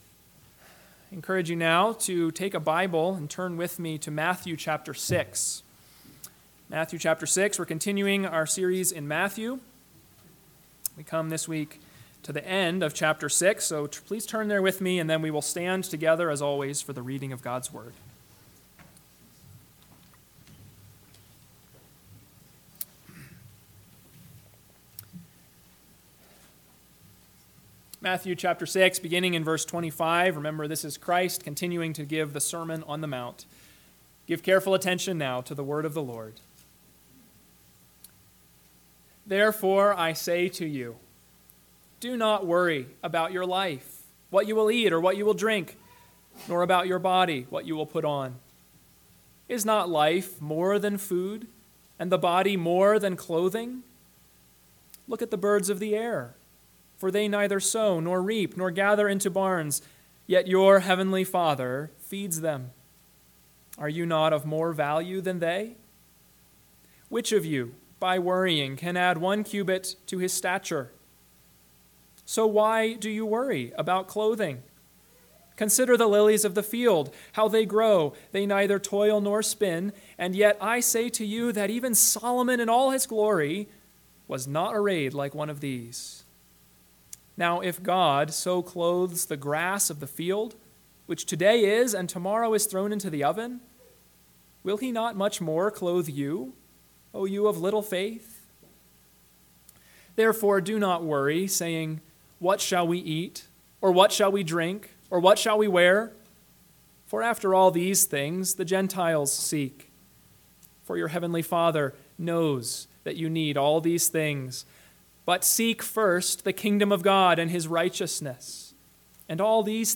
AM Sermon – 4/23/2023 – Matthew 6:25-34 – Why Do You Worry?